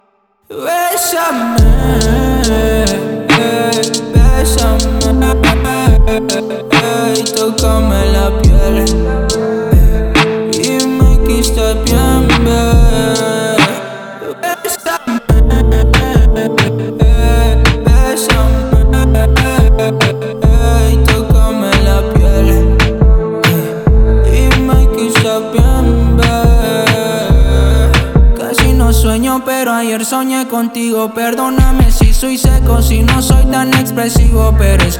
Жанр: Латиноамериканская музыка / Русские
# Urbano latino